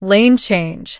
audio_lane_change.wav